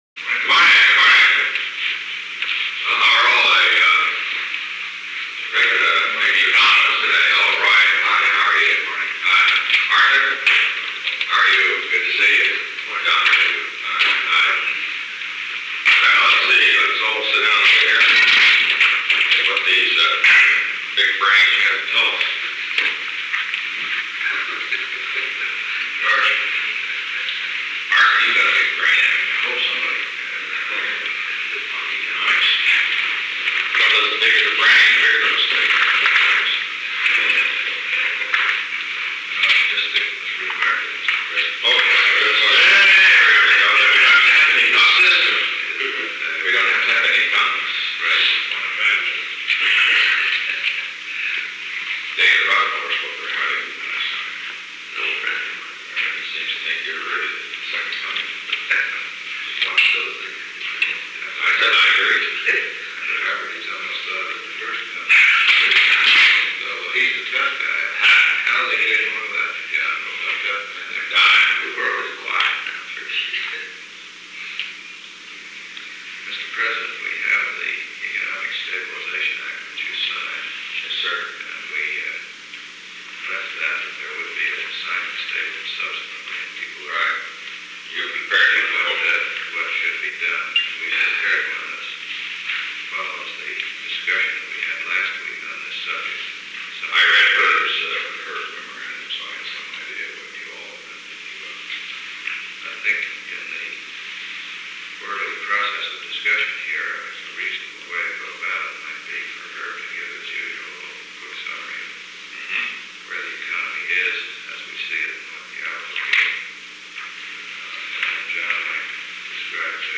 Conversation No. 909-3 Date: May 2, 1973 Time: 8:44 am - 9:25 am Location: Oval Office -6- NIXON PRESIDENTIAL LIBRARY AND MUSEUM Tape Subject Log (rev. October-2012) Conversation No. 909-3 (cont’d) The President met with Spiro T. Agnew, George P. Shultz, Herbert G. Stein, Arthur F. Burns, Roy L. Ash, John T. Dunlop, and Kenneth R. Cole, Jr. Greetings Quadriad -Intellect -Burns -President’s conversation with David Rockefeller, May 1 Economic Stabilization Act -President’s signing statement -Stein
Secret White House Tapes